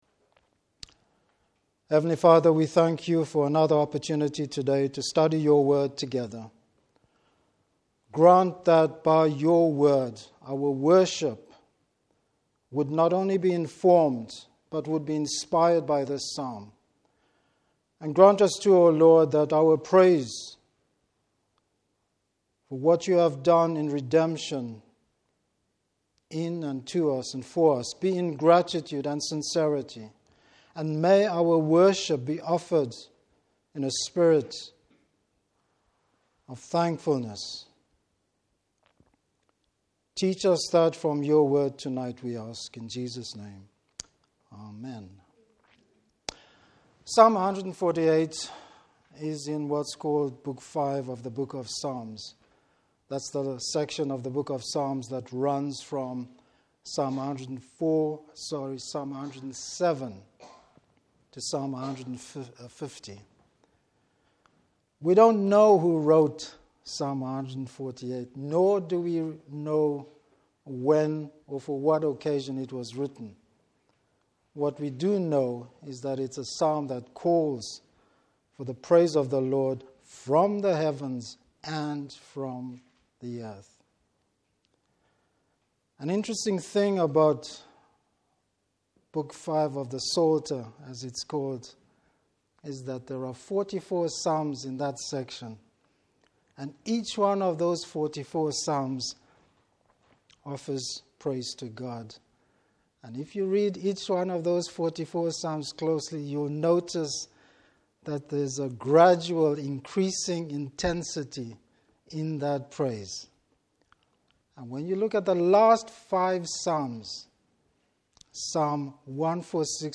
Service Type: Evening Service Why praise God?